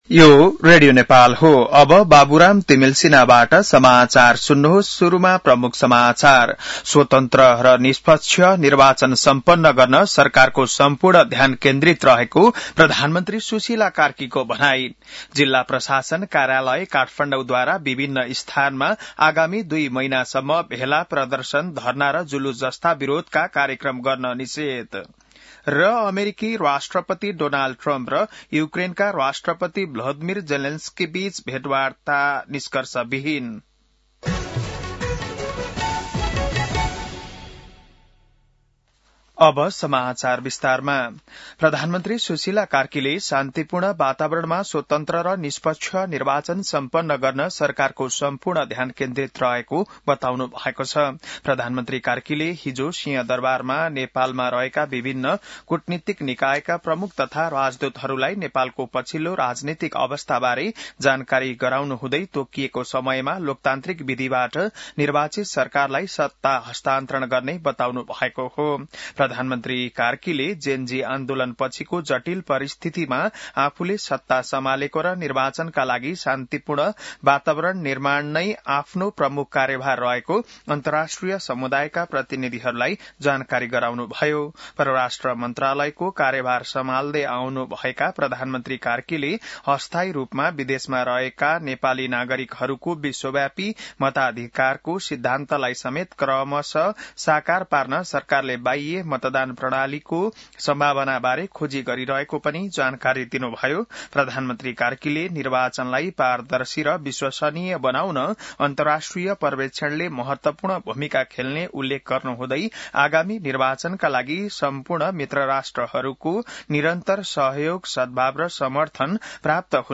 बिहान ९ बजेको नेपाली समाचार : १ कार्तिक , २०८२